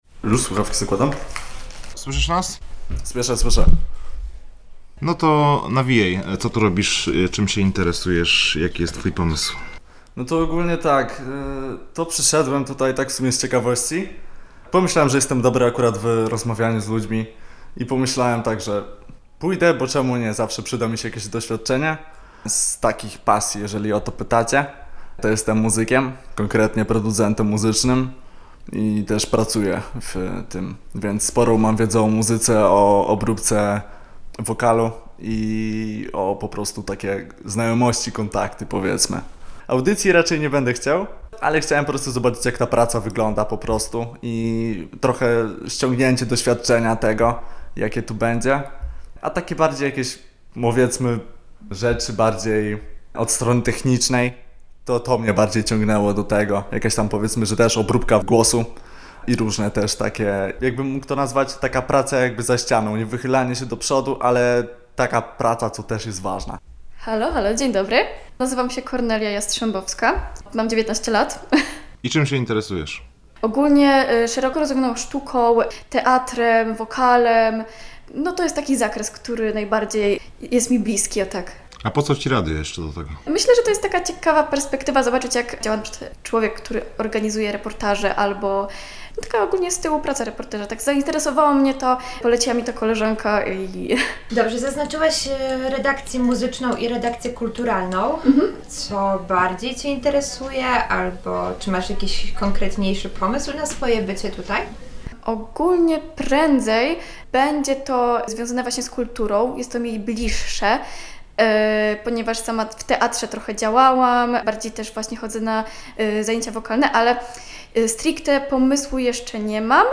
0703-MA-nabor-wiosenny.mp3